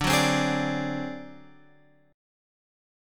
D7b5 chord {10 9 10 x 9 10} chord